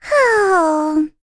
Luna-Vox_Sigh1.wav